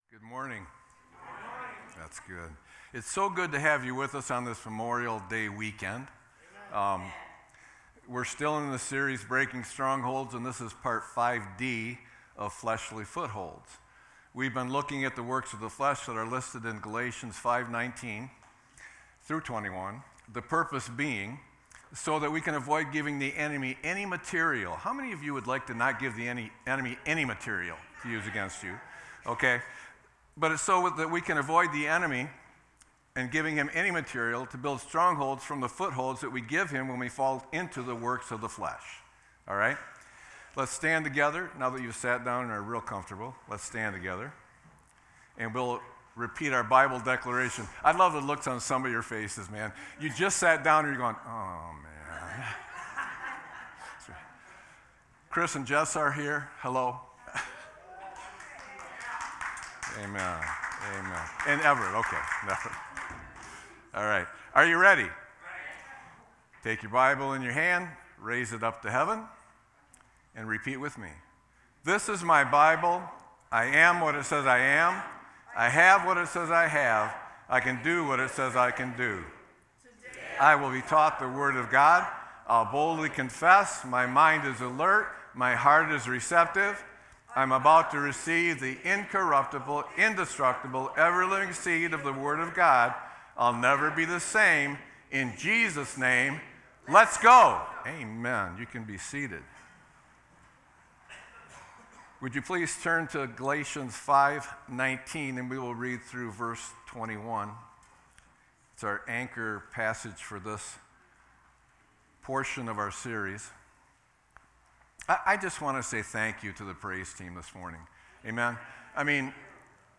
Sermon-5-26-24.mp3